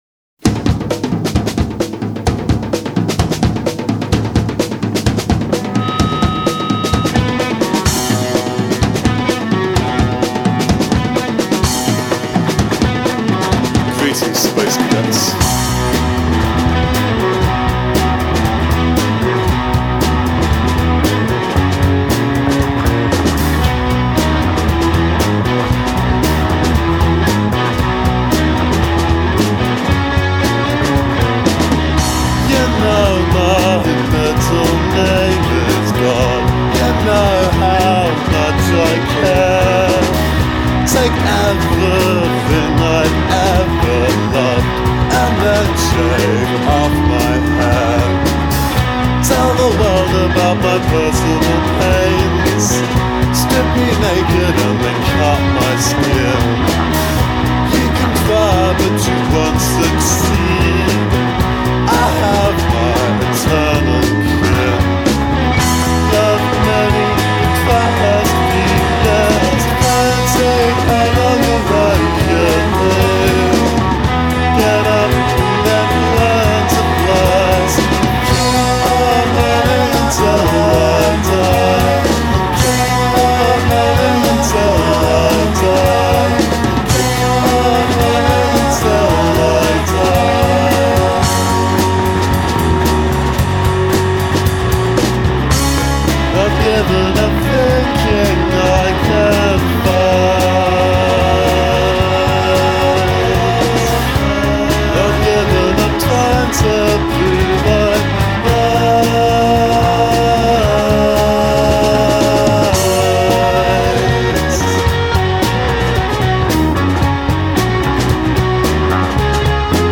headed into the studio